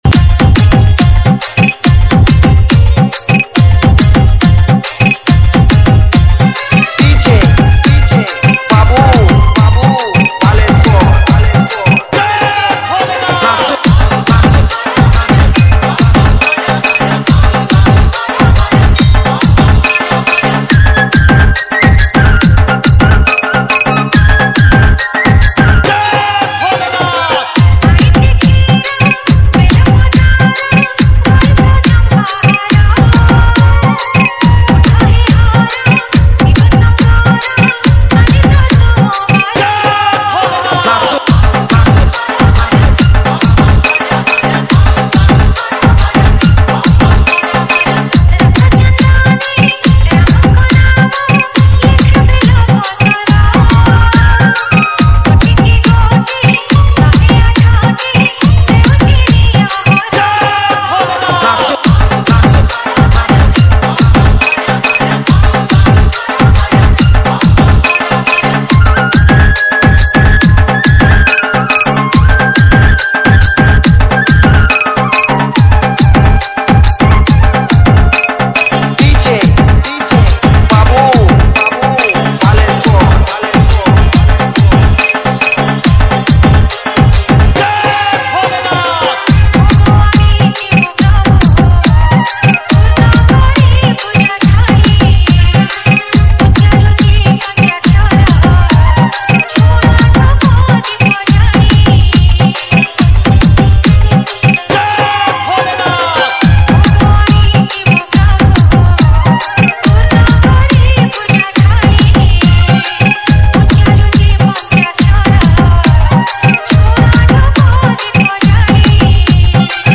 ODIA BHAJAN DJ REMIX